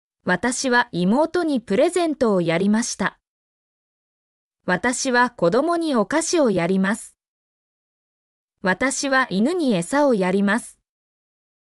mp3-output-ttsfreedotcom-39_zTskOB9x.mp3